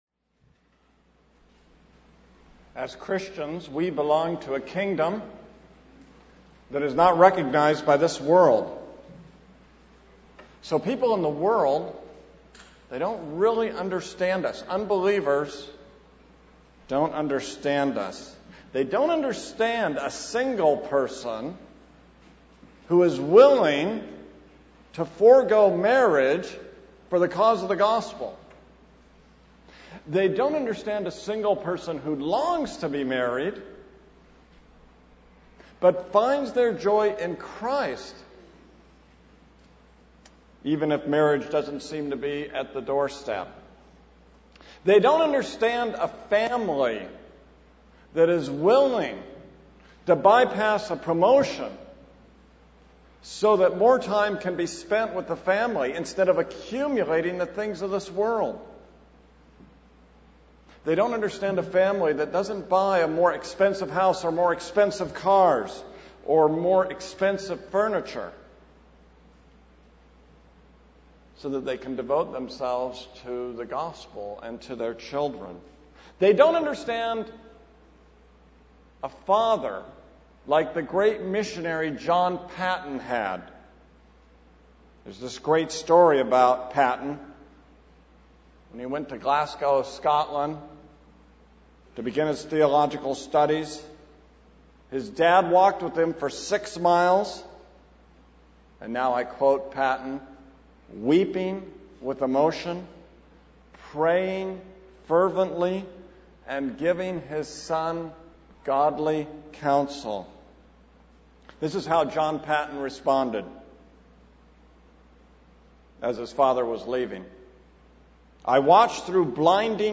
This is a sermon on 2 Samuel 23:1-24:25.